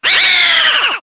One of King Boo's voice clips in Mario Kart Wii